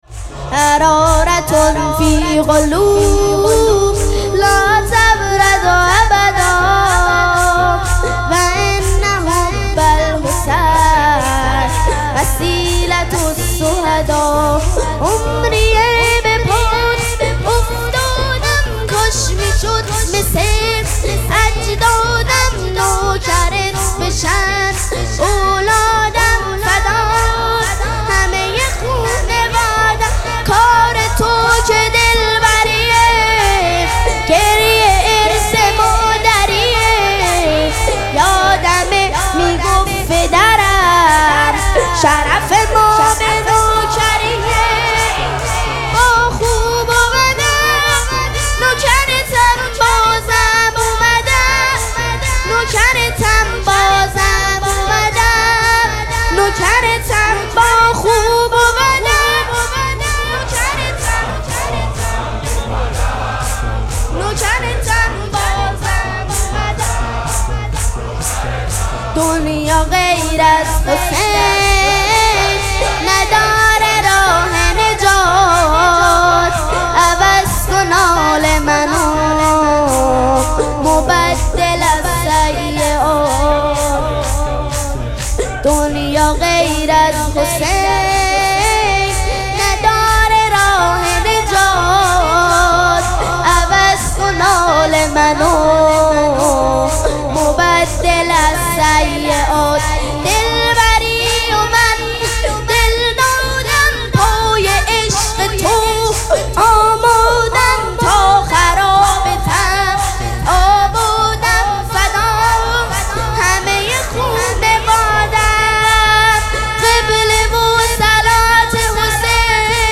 مراسم عزاداری شهادت امام صادق علیه‌السّلام
شور